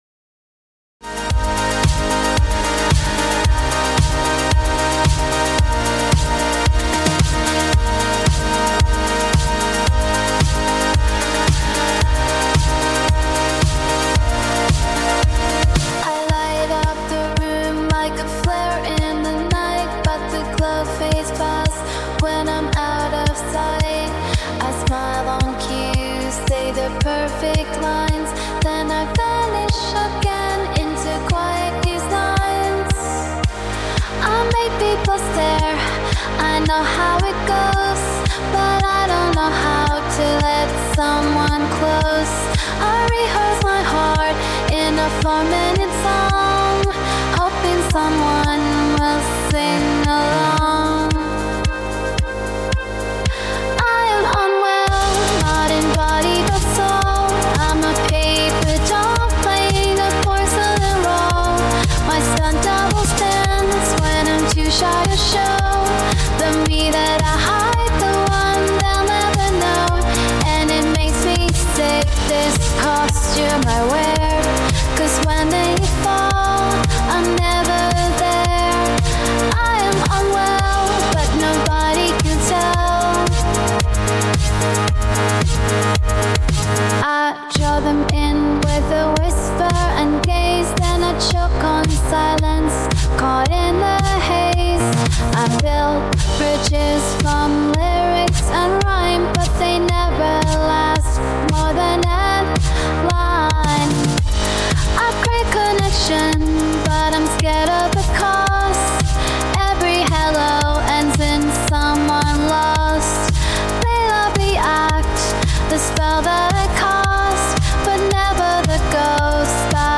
• Genre: Indie Pop/Electro